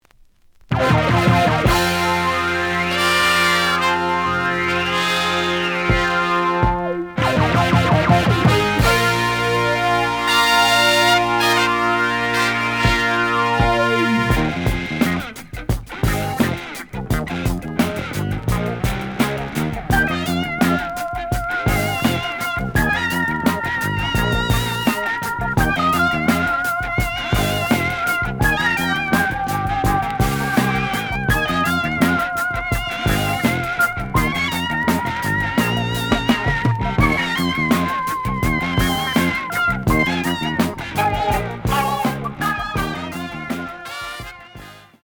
The audio sample is recorded from the actual item.
●Format: 7 inch
●Genre: Jazz Funk / Soul Jazz